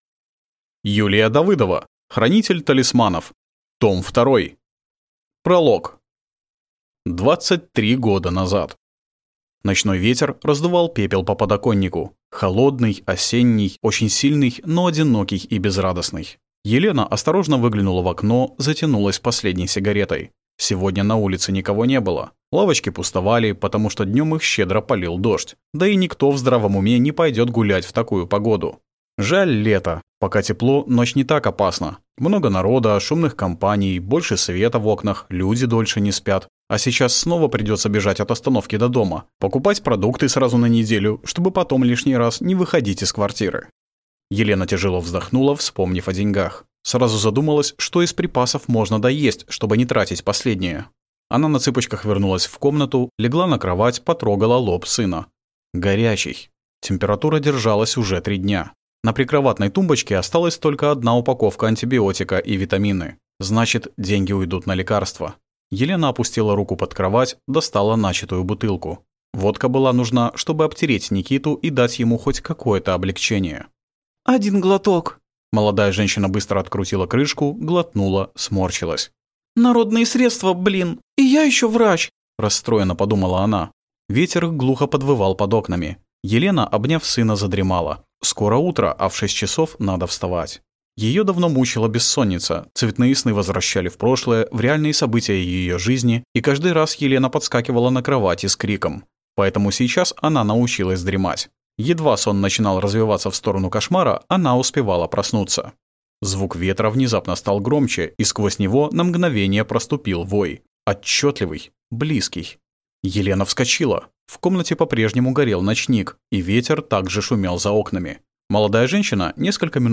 Аудиокнига Хранитель талисманов II | Библиотека аудиокниг